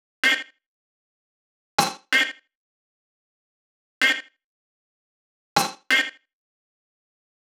VDE 127BPM Rebound Cutted.wav